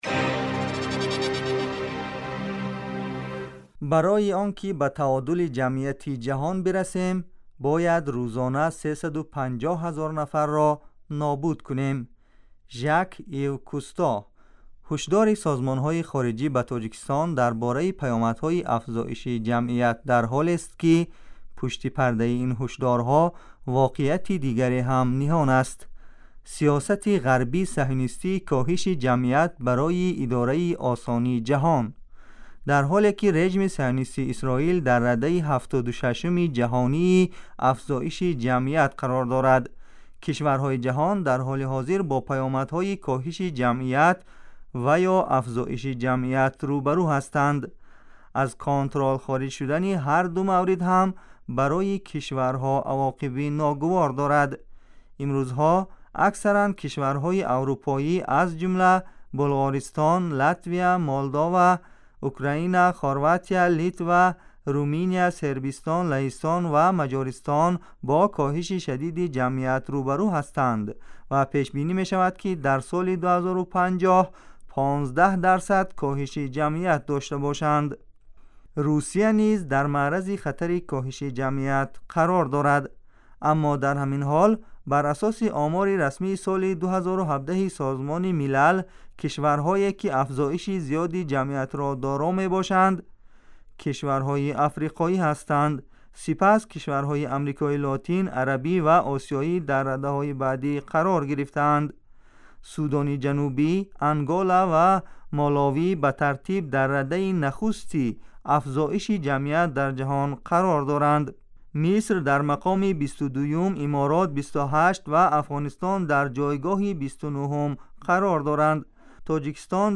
Шарҳи ҷузъиёти бештари ин мавзӯъро дар гузориши вижаи ҳамкорамон